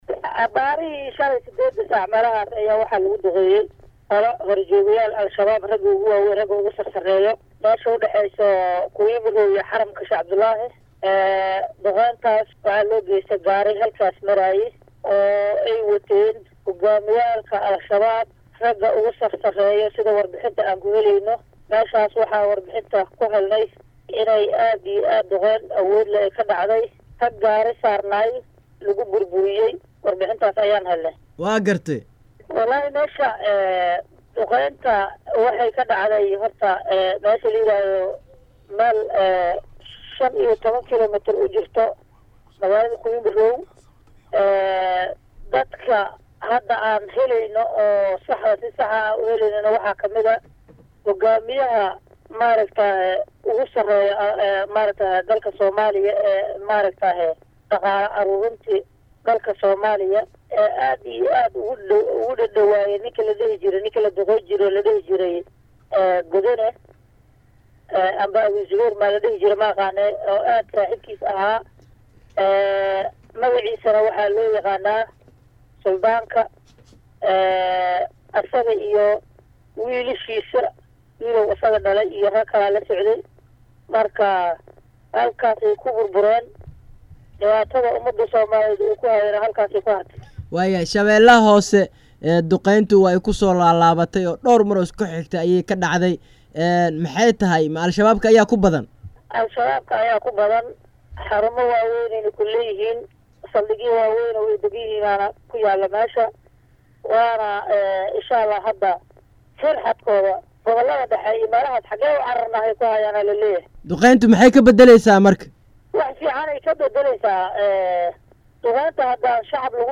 Bashiir Maxamed Yuusuf oo ah Gudoomiye ku xigeenka Dhanka Amniga Degmada Baraawe ee Gobolka Shabeelaha Hoose oo la Hadlay Radio Muqdisho Codka Jahmuuriyada Soomaaliya ayaa sheegay Inay Duqeymahaan oo ay gaysteen Diyaarado Dagaal lala Beegsaday Saraakiil ka tirsan Maleeshiyaadka Nabad diidka ah Al shabaab.
Bashiir-maxamed-yuusuf-gudoomiye-ku-xigeenka-amniga-degmadda-baraawe.mp3